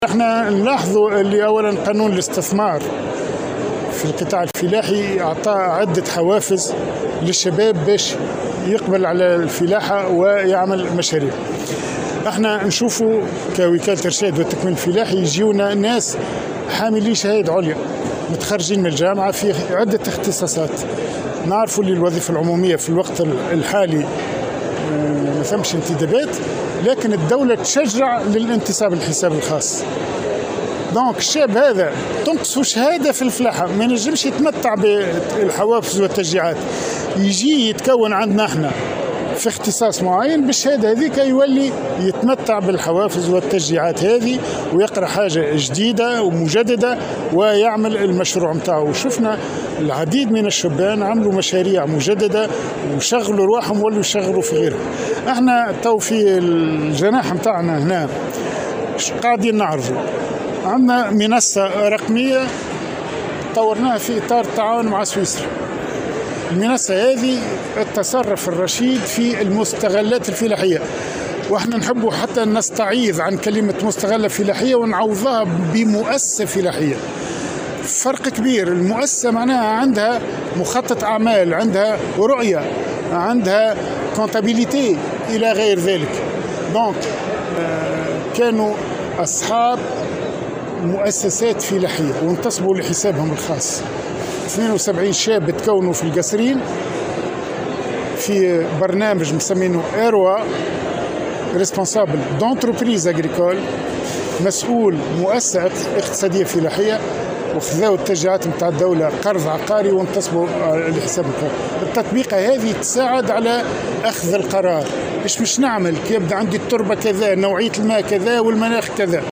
وقال الزهاني، في تصريح لمراسل الجوهرة أف أم، على هامش انعقاد الصالون الدولي للاستثمار الفلاحي والتكنولوجيا، أن وكالة الارشاد والتكوين الفلاحي وضعت على ذمة الشبان الراغبين في الاستثمار في القطاع الفلاحي تطبيقة، طورتها بالتعاون مع سويسرا، لمساعدتهم على أخذ القرار وحسن التعامل مع مختلف الوضعيات التي تواجههم في القطاع الفلاحي.